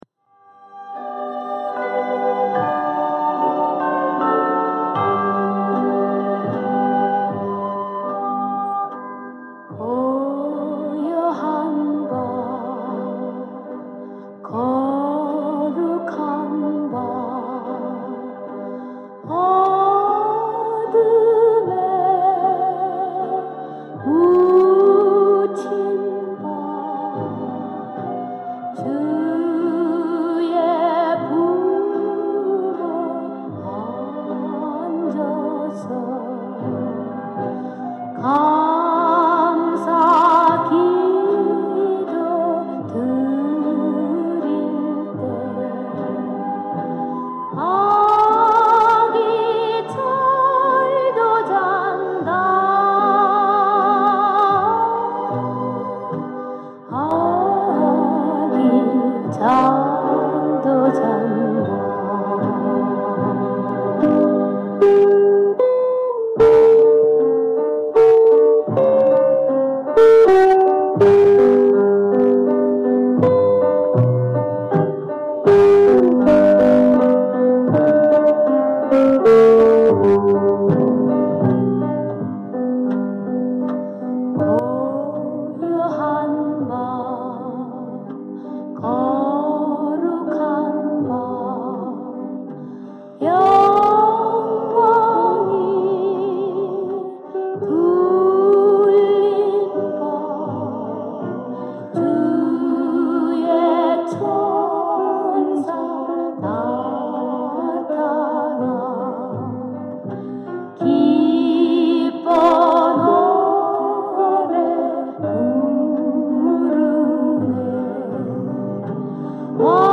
음반구입 복각한 음원